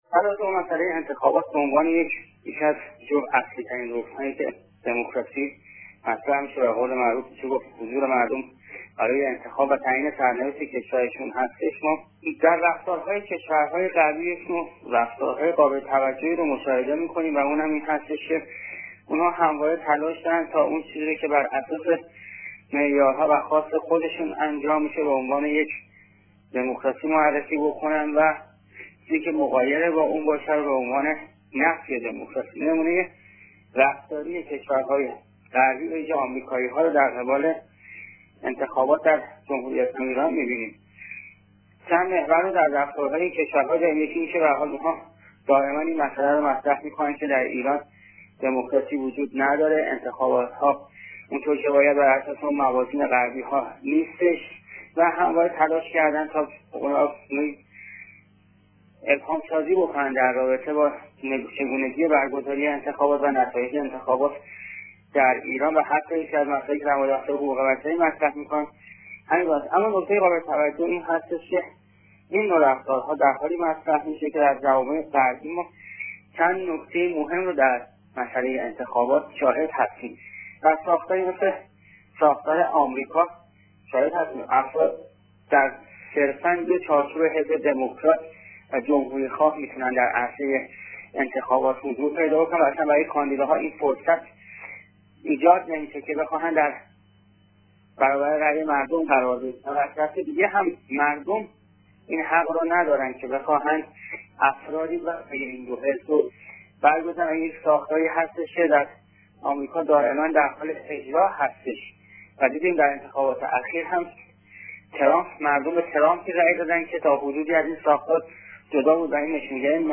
روزنامه نگار و تحلیل گر مسائل سیاسی در گفتگو با خبرنگار رادیو دری